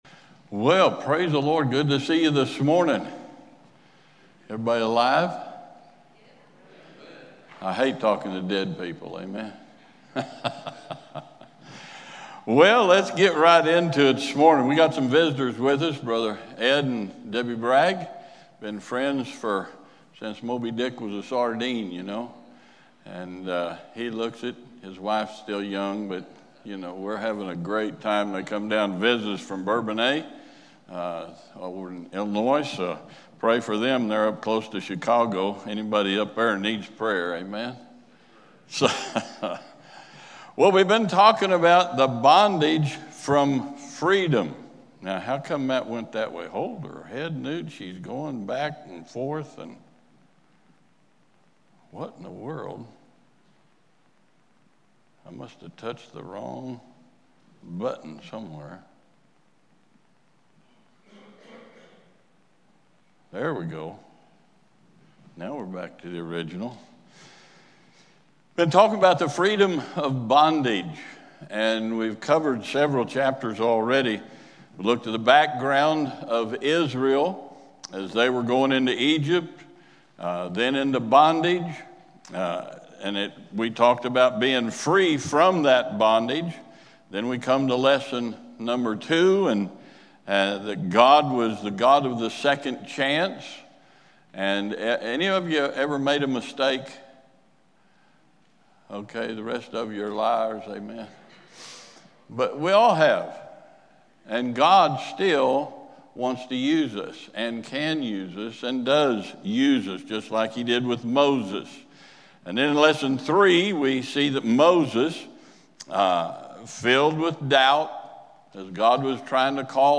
Sermons | First Baptist Church
Sunday School